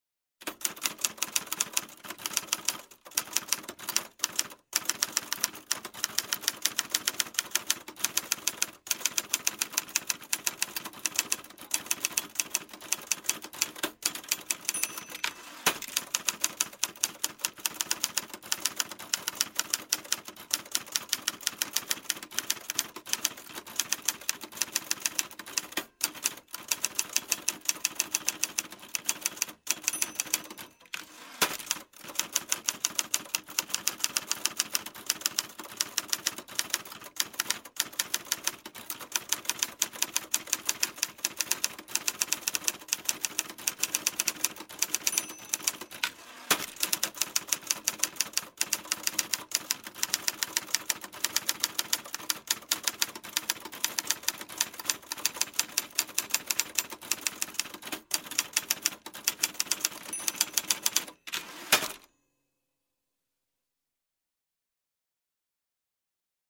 Звуки печатания
Звук печати пальцами по клавишам обычной пишущей машинки